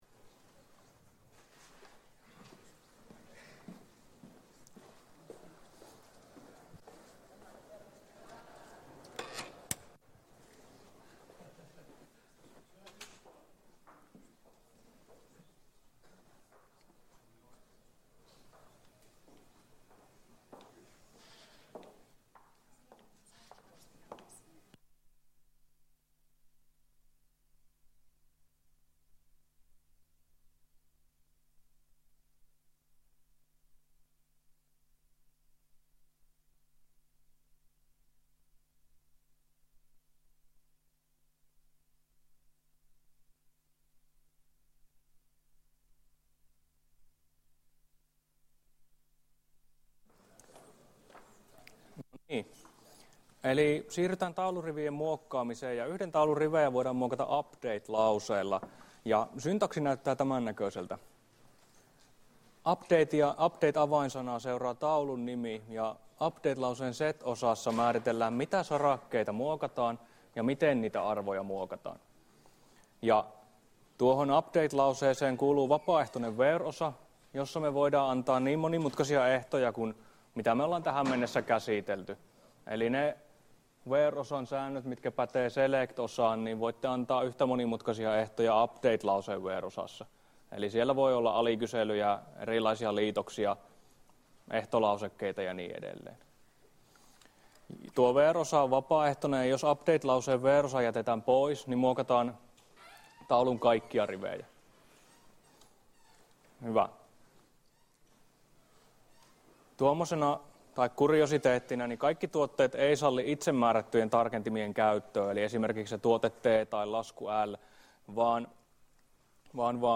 Luento 8 — Moniviestin